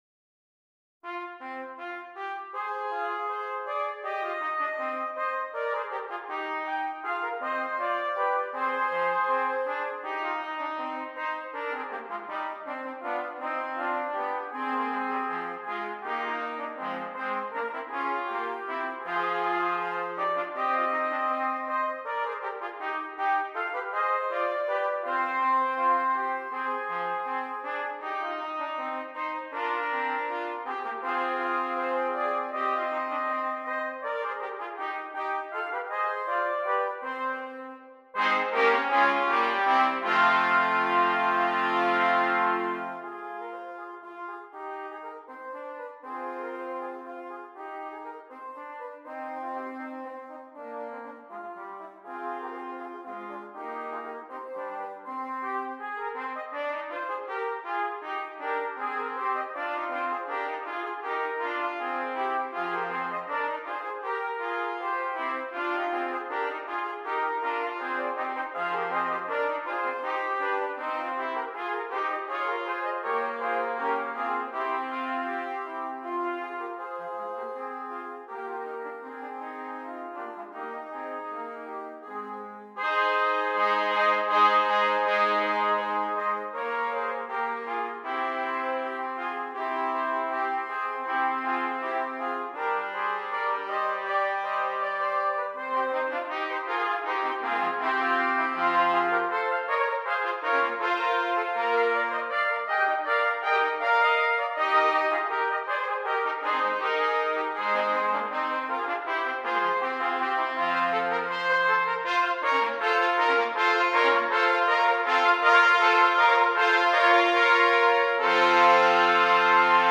Brass
6 Trumpets